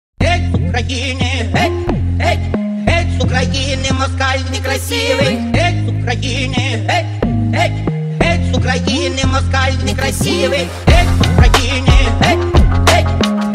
Танцевальные рингтоны
веселые
мужской голос